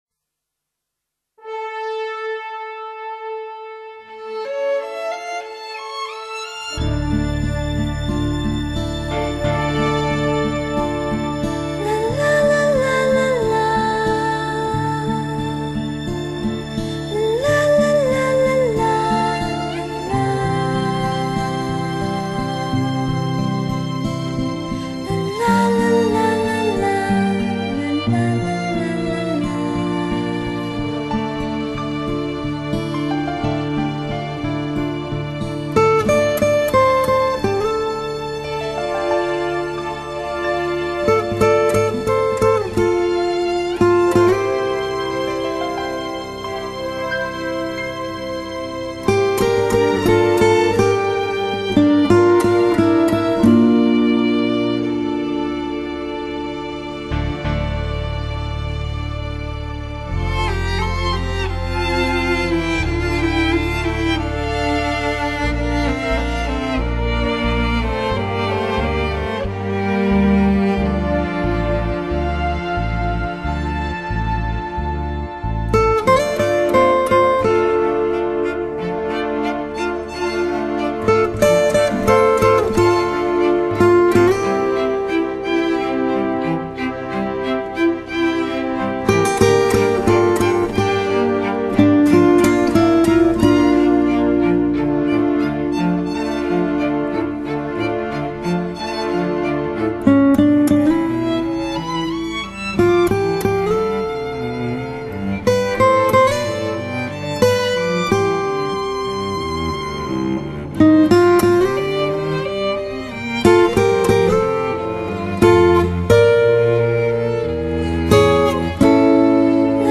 華麗、親切和諧的音樂氣氛錄音出色，配器清新，效果靚絕，
音色清晰明亮，爽朗怡人，層次感佳，豐富的配器絕對發燒，令人耳目一新。